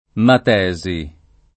matesi [ mat $@ i ] s. f. — grecismo per «scienza; matematica»